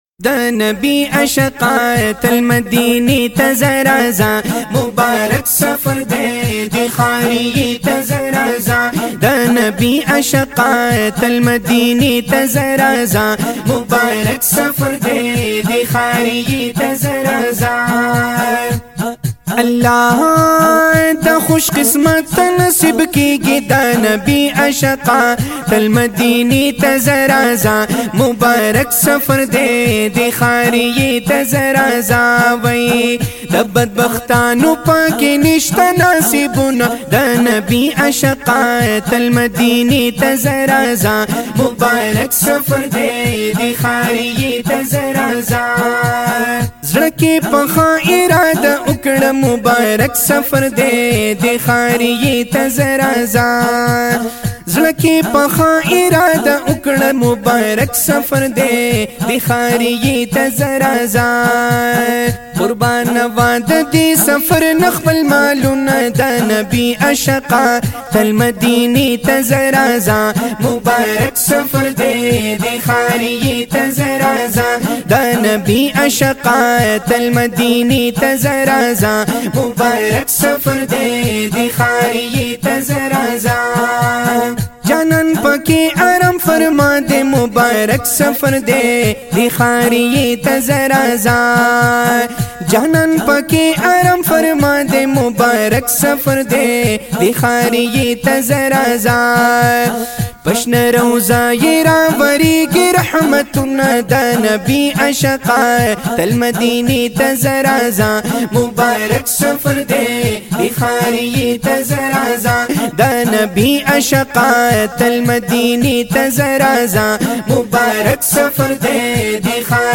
Islamic Naat 2025